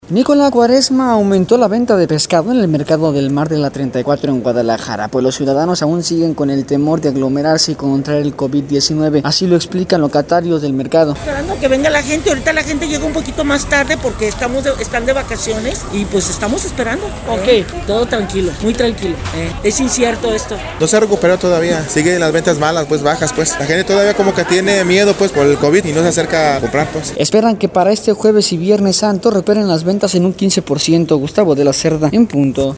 Ni con la Cuaresma aumentó la venta de pescado en el Mercado del Mar de la 34 en Guadalajara, pues los ciudadanos aún siguen con el temor de aglomerarse y contraer el Covid-19. Así lo explican locatarios del mercado: